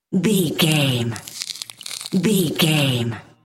Creature eating flesh peel short
Sound Effects
Atonal
scary
ominous
eerie
horror